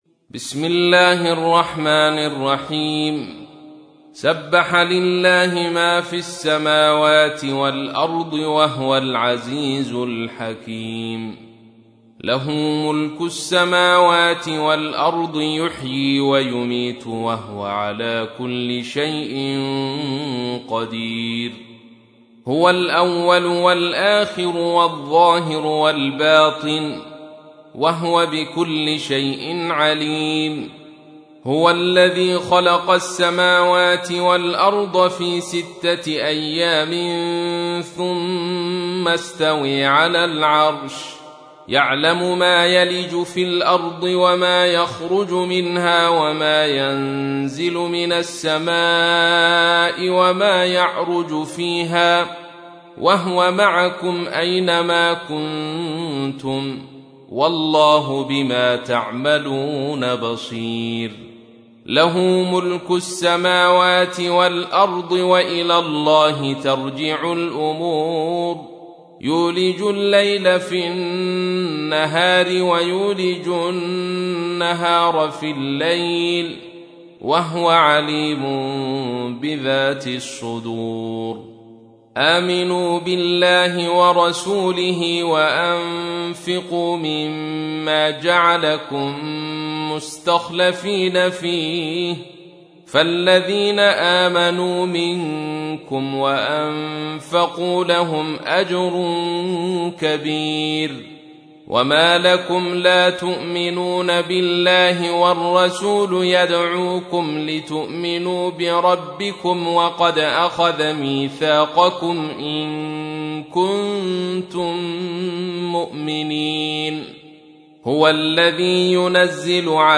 تحميل : 57. سورة الحديد / القارئ عبد الرشيد صوفي / القرآن الكريم / موقع يا حسين